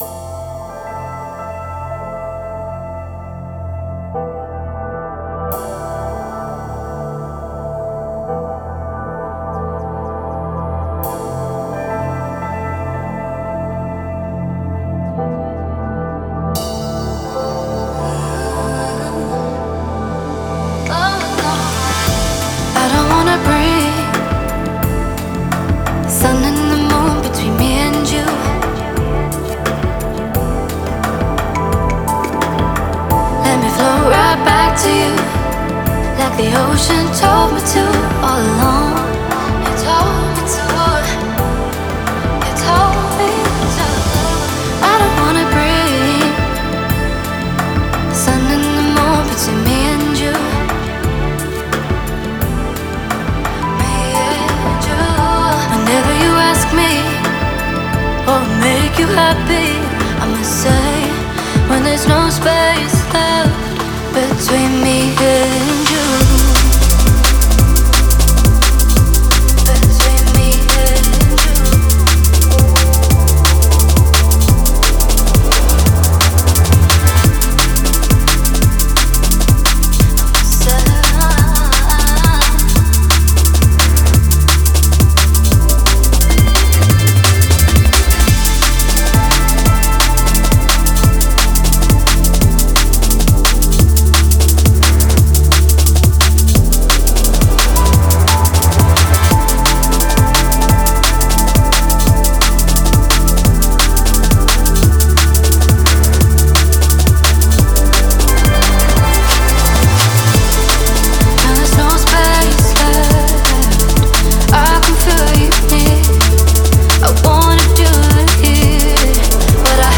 Стиль: Drum & Bass